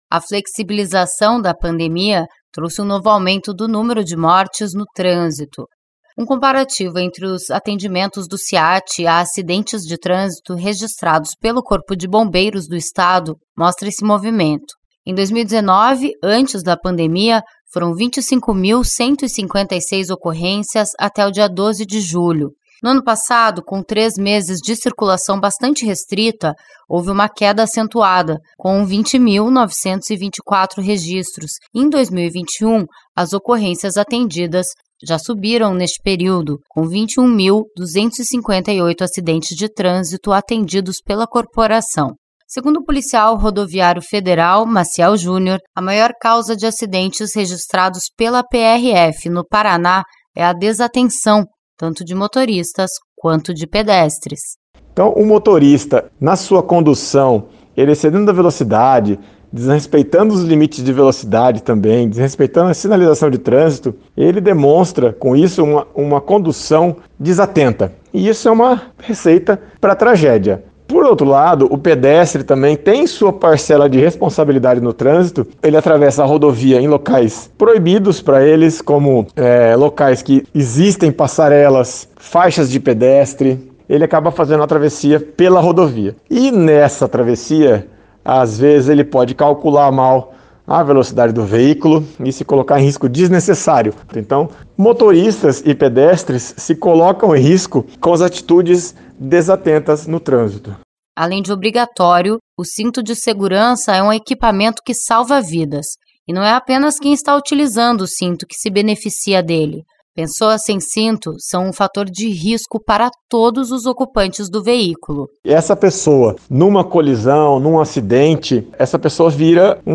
Mas as distrações são um grande perigo que podem levar a acidentes graves e ou fatais. A gente preparou uma reportagem para você lembrar de tudo aquilo que deve prestar a atenção ao pegar no volante.